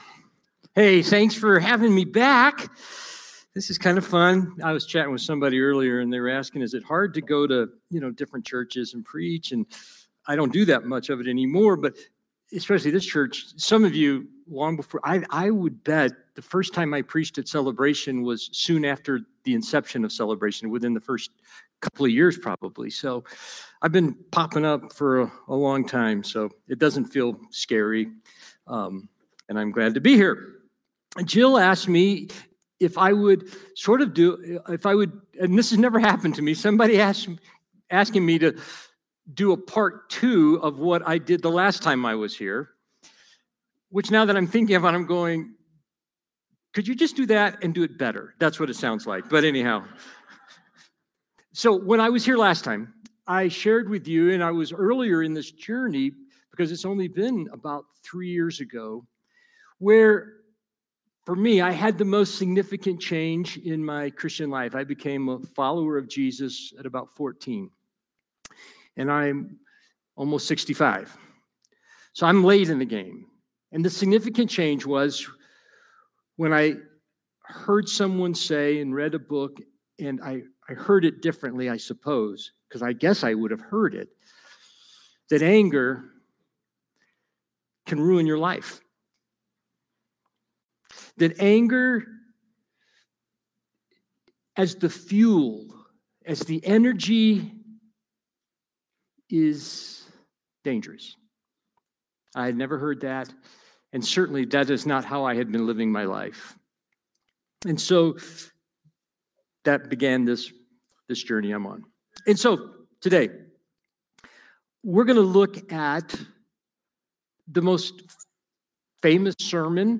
Sermon from Celebration Community Church on October 19, 2025